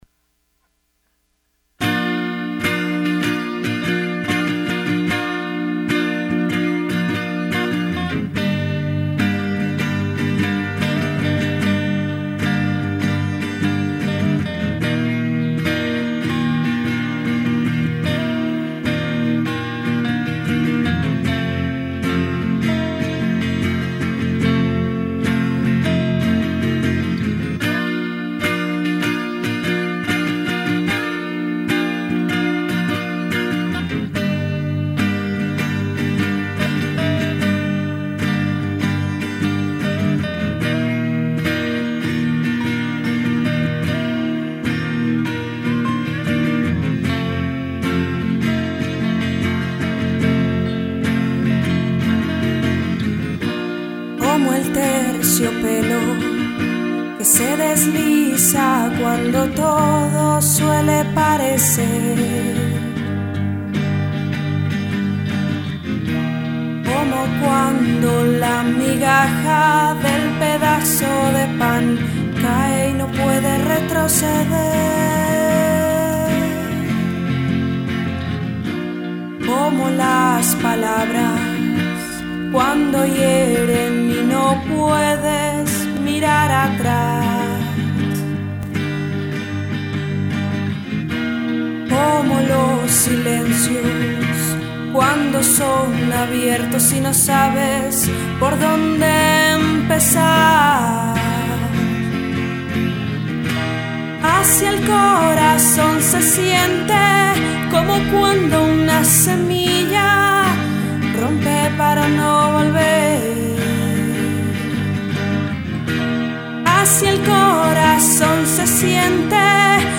Voz
Guitarras